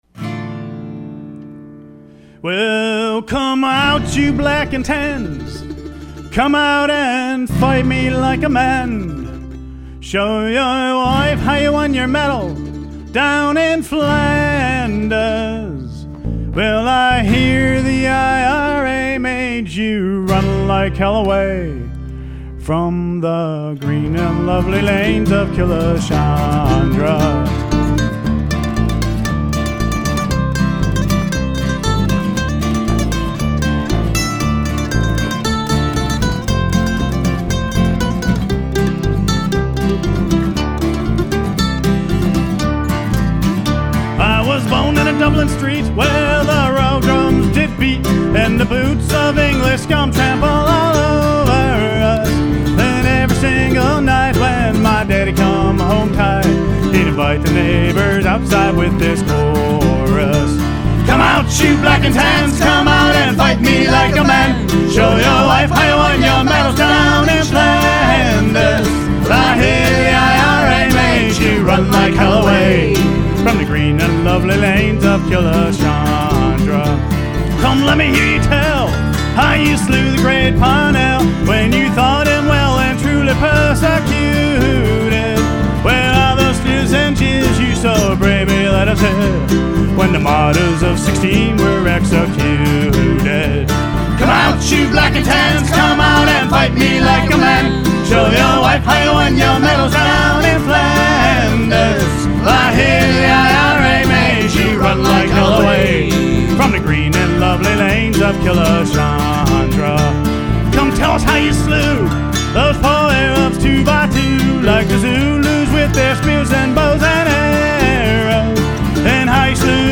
Cleveland's Irish Acoustic Rock